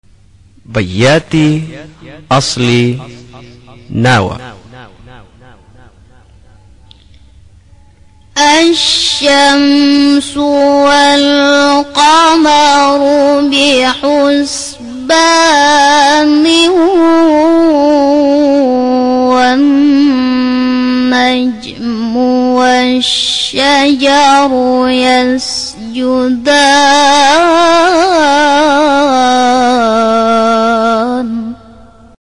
بیات اصلی نوا 5.mp3